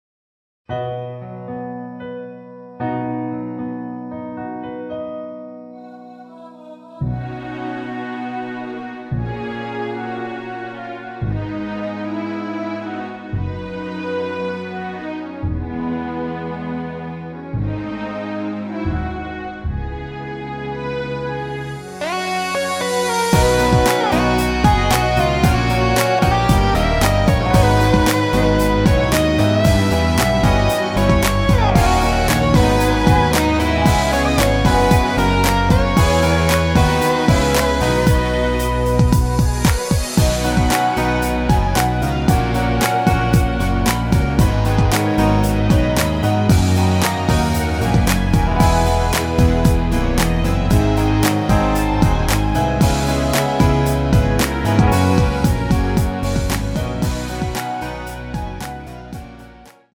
전주 없이 시작 하는 곡이라 노래 하시기 편하게 전주 2마디 만들었습니다.
원곡의 보컬 목소리를 MR에 약하게 넣어서 제작한 MR이며